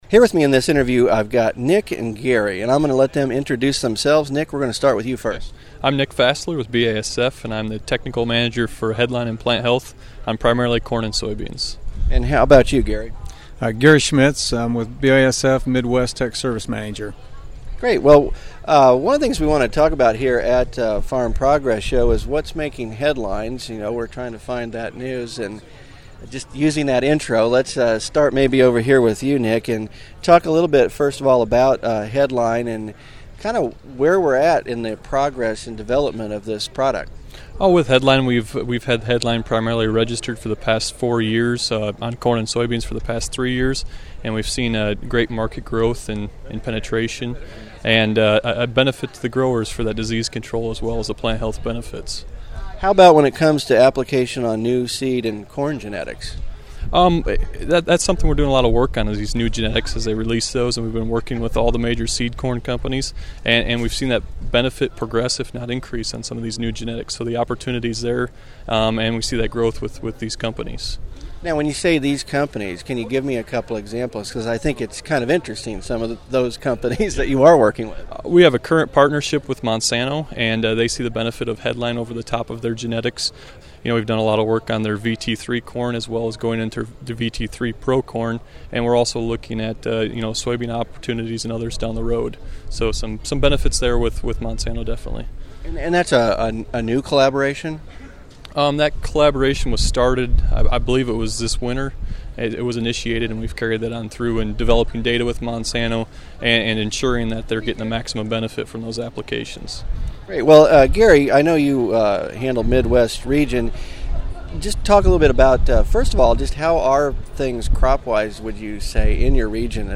AgWired coverage of the 2008 Farm Progress Show